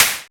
Clap
ED Claps 14.wav